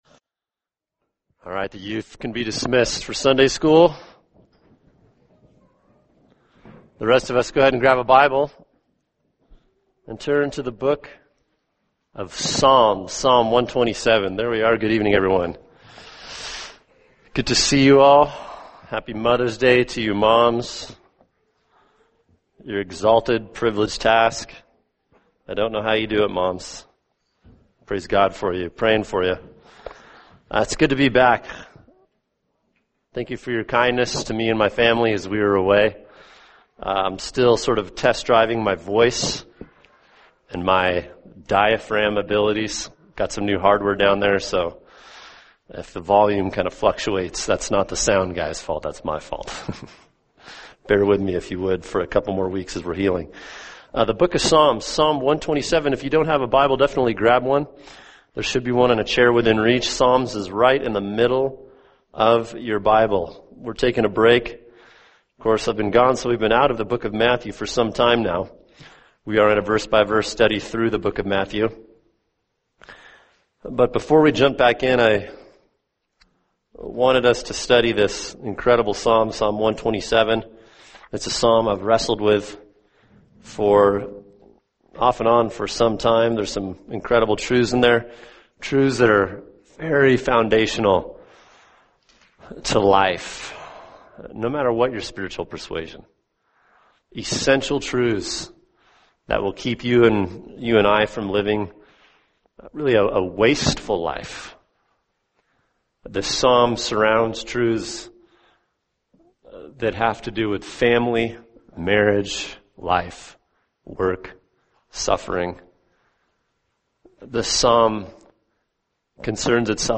[sermon] Psalm 127 The Source of All Your Good | Cornerstone Church - Jackson Hole